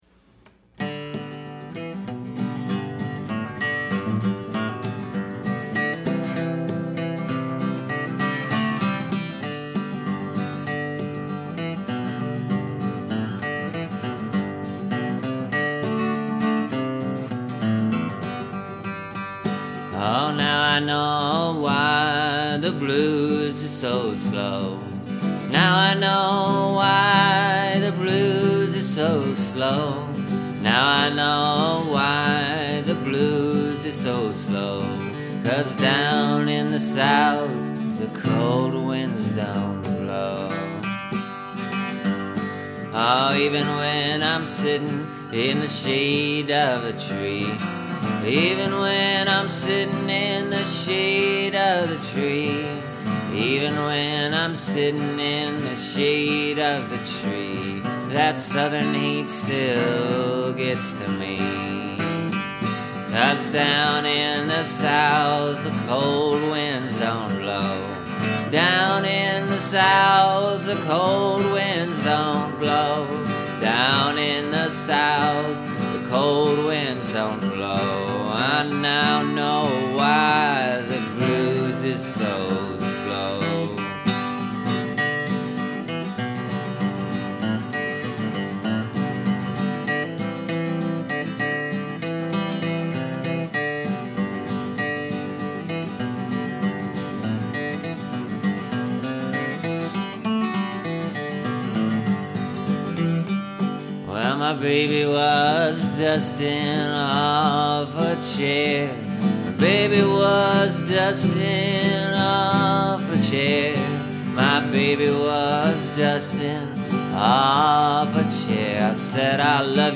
Now, the previous version was pretty much a straight up blues progression. This one is too, might sound different, but it’s still blues. Just maybe a little more hillbilly blues than delta blues.